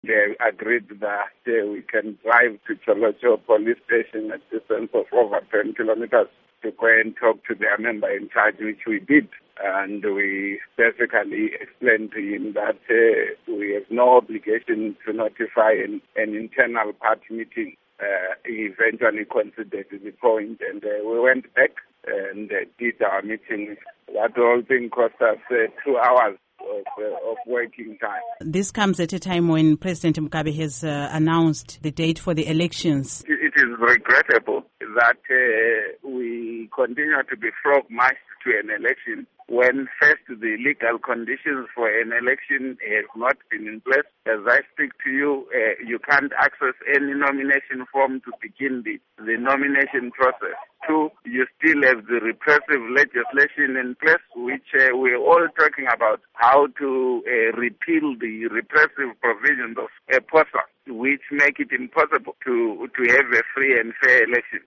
Interview with Welshman Ncube